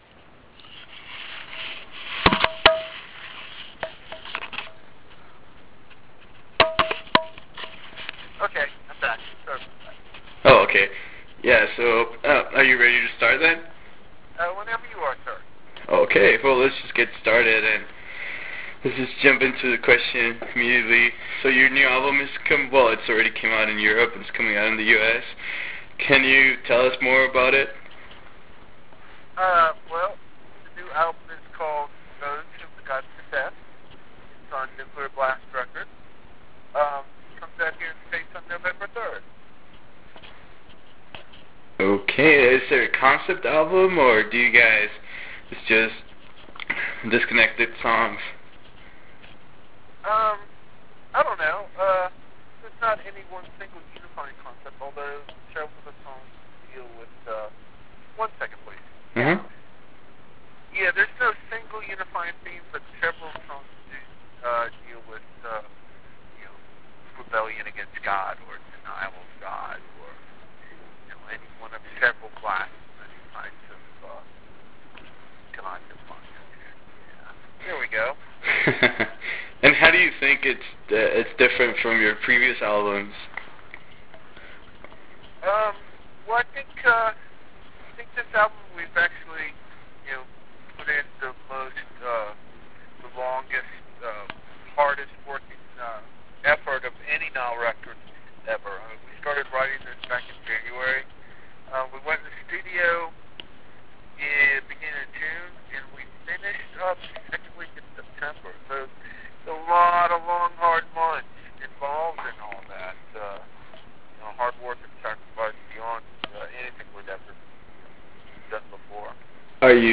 Interview with Nile (Karl Sanders)
Interview with Karl Sanders from Nile.wav